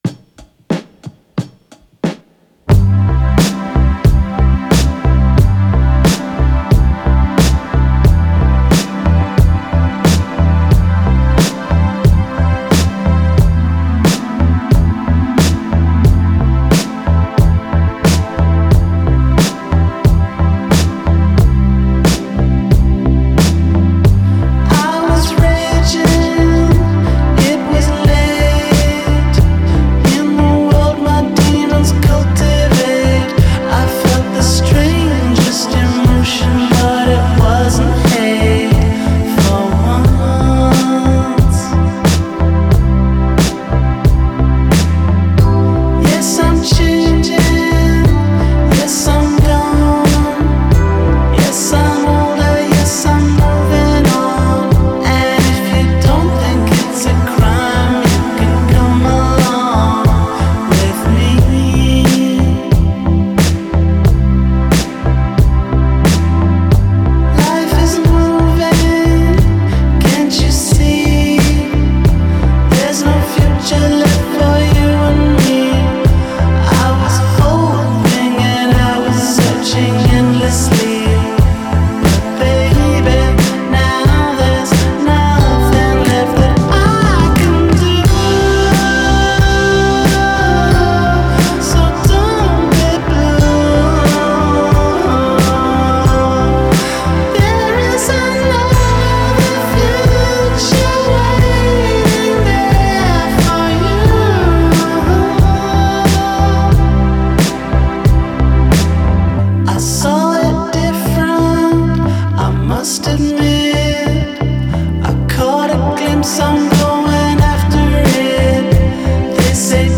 Genre: Indie Rock, Psychedelic